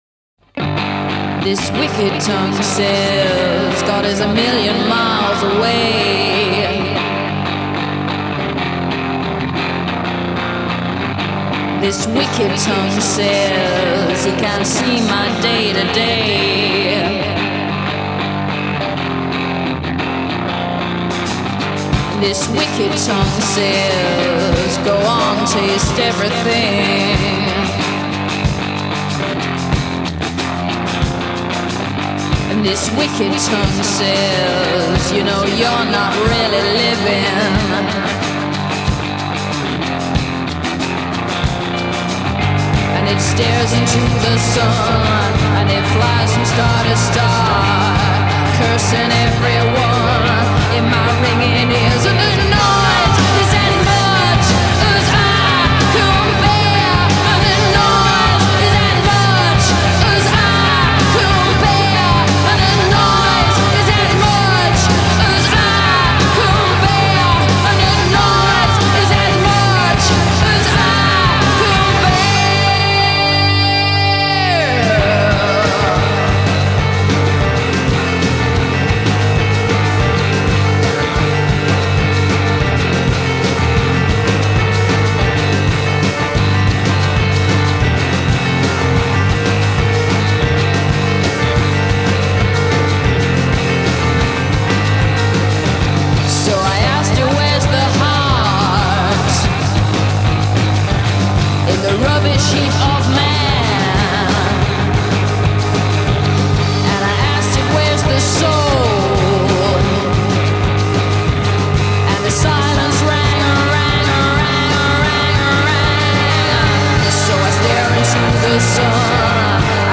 ferocious outtake